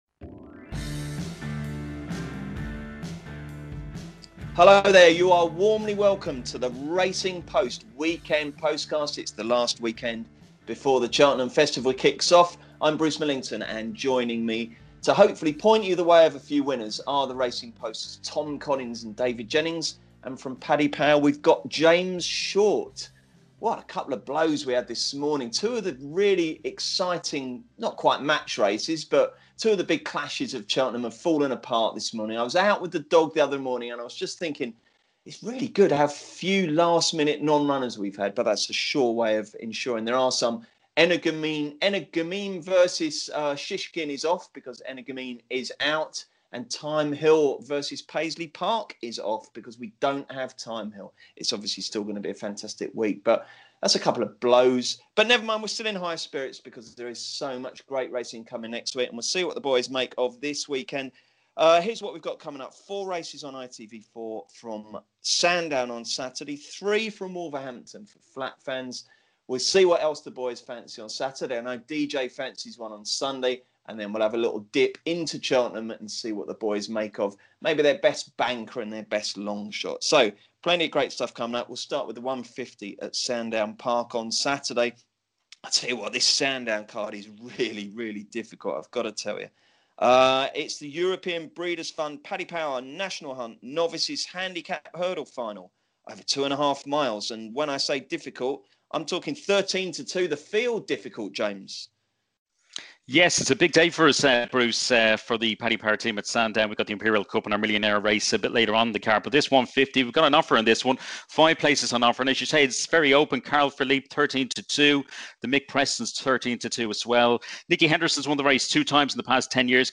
The panel discuss the feature race of the weekend, the Imperial Cup, are they with or against favourite Natural History? As well as that, our expert tipsters give their NAP for the weekend and provide a Cheltenham longshot for the Festival next week.